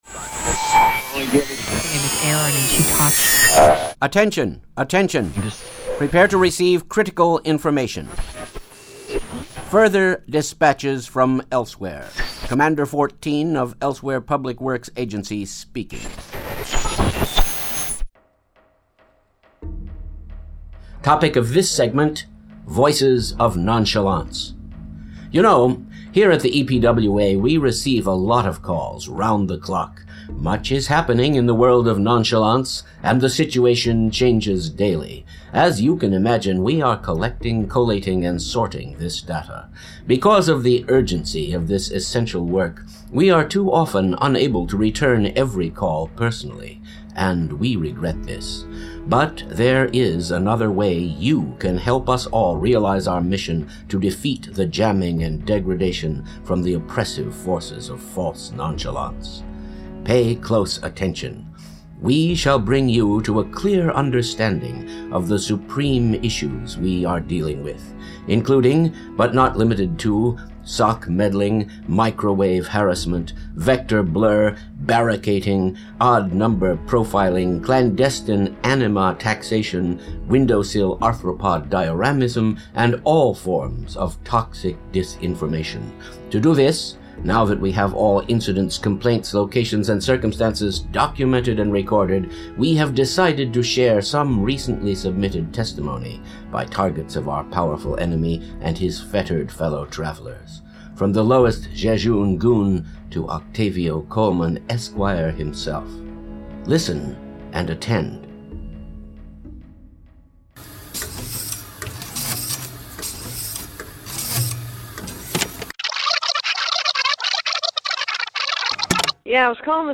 These ones with the phone messages (this and the Microwave Harassment mp3) are some of my favorites, for sure!